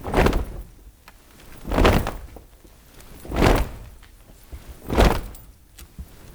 STOMPS    -R.wav